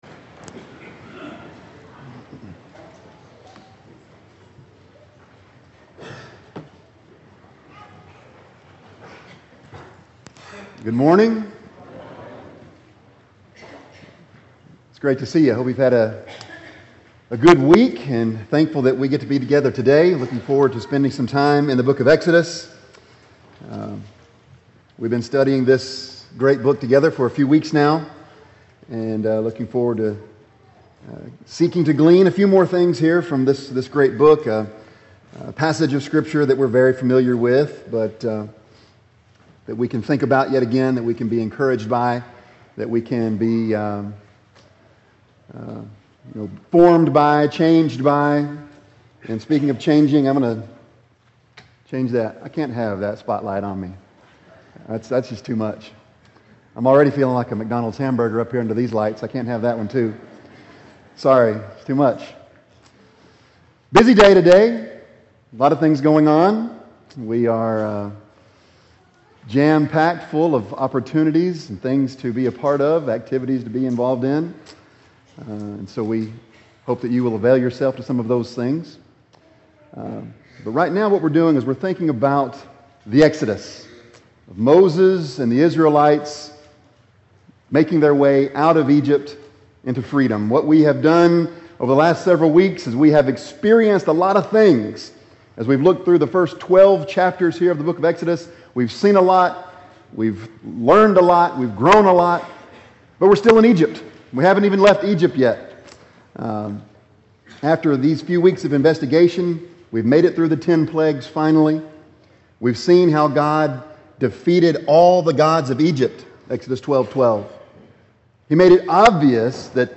Bible Text: Exodus 13:17- 18:27 | Preacher